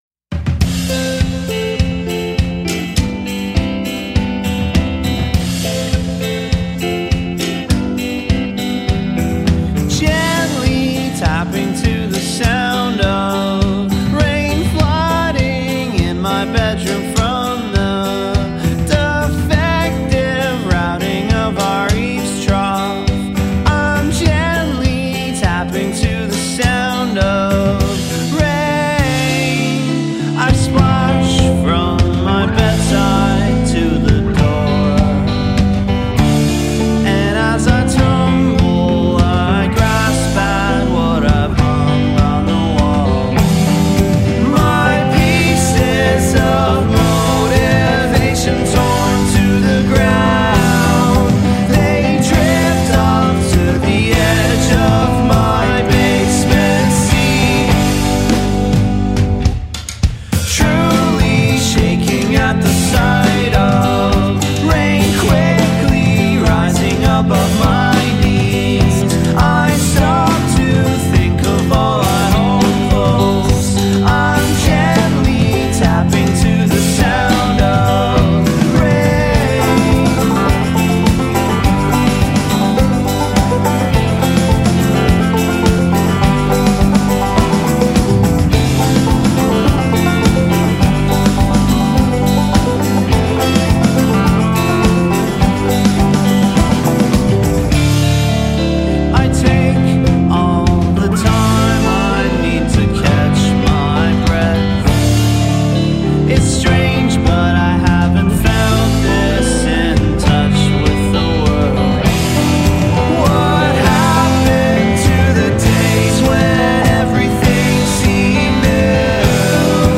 post-rock & indie-folk group